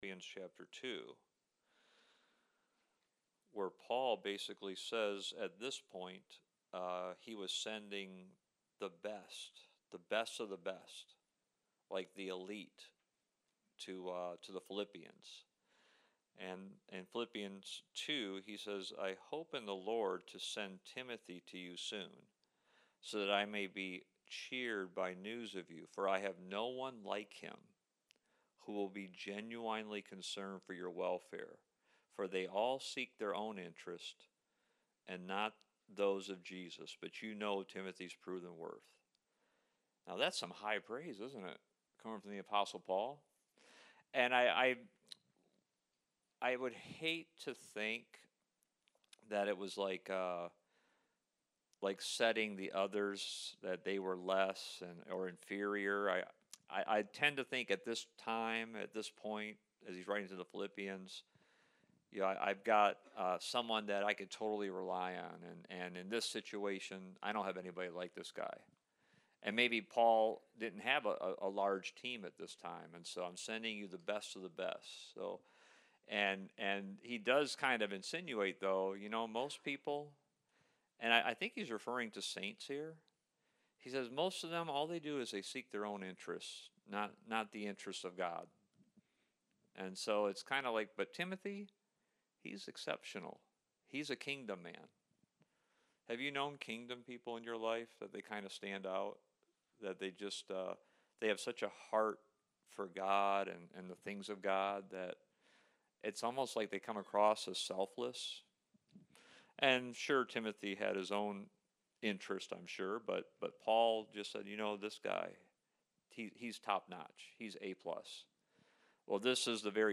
Service Type: Wednesday Night